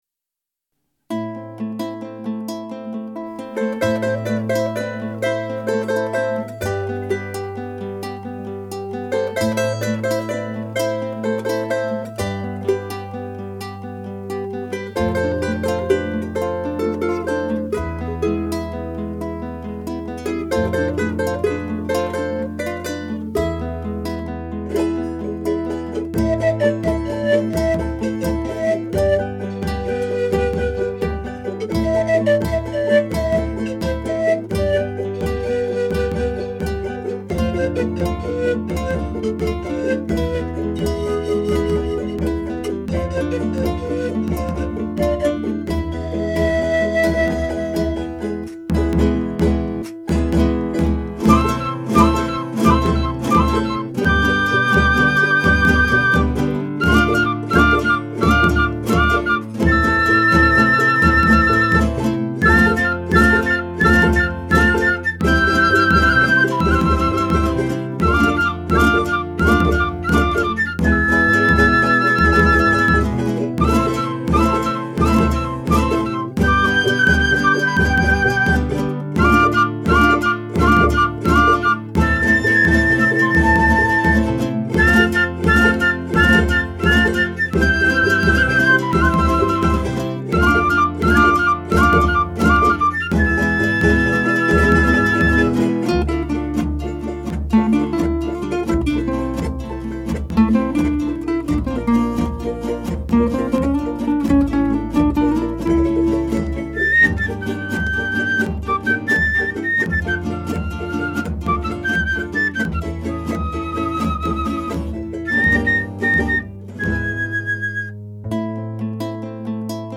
別々に録音した楽器やパソコンで作ったパーカッションをミックスして完成します。
スタジオは鍼灸院の待合室です。